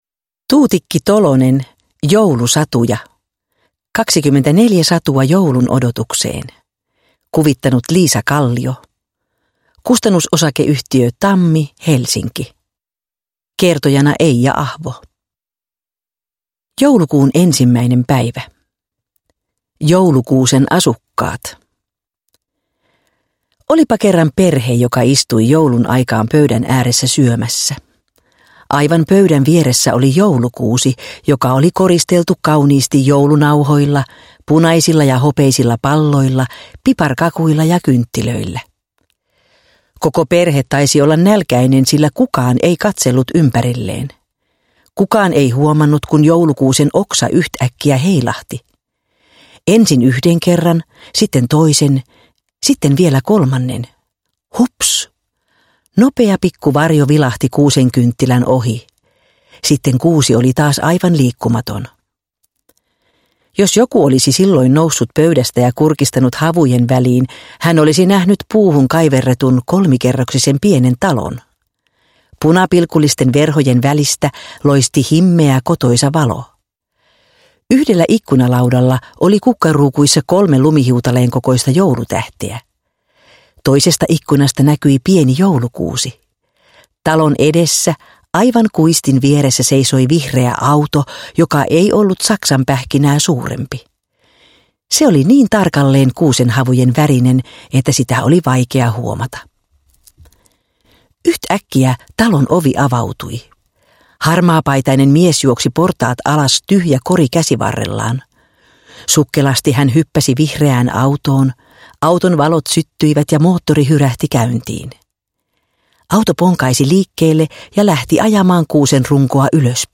Joulusatuja – Ljudbok – Laddas ner